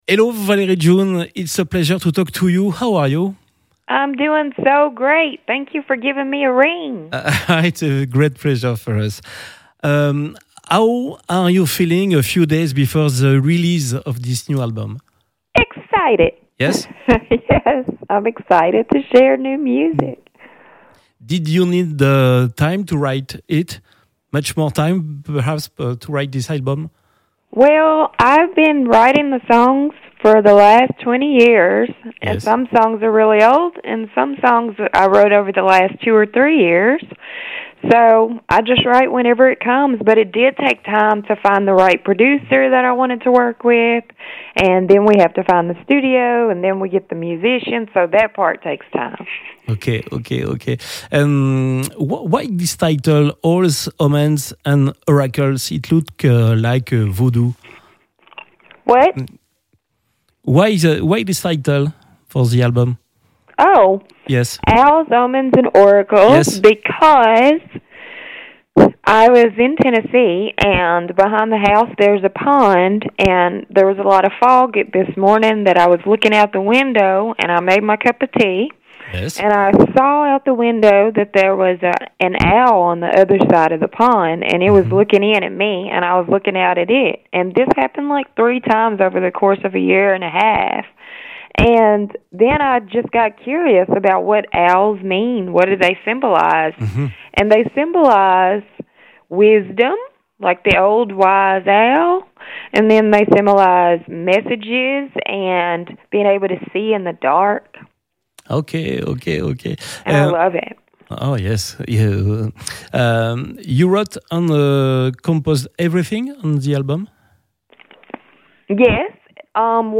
Interview Jazz Radio